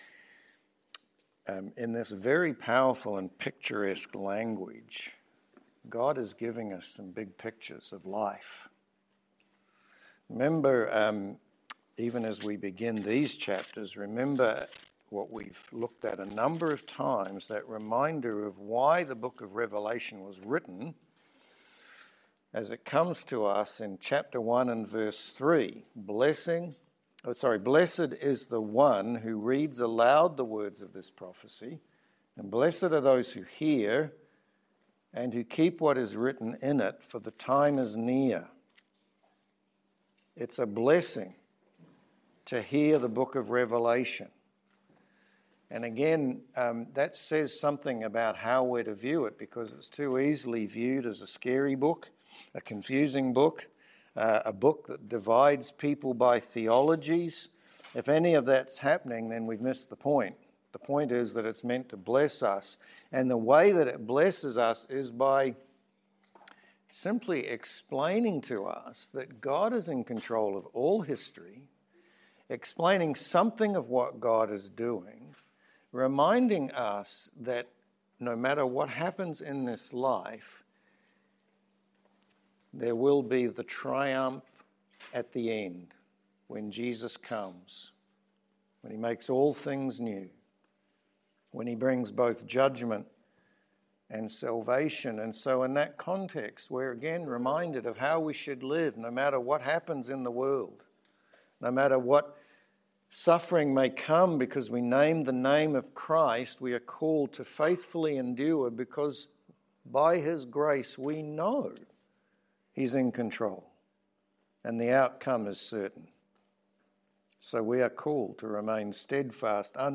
Revelation 10-11 Service Type: Sermon We continue our study in Revelation with chapters 10-11.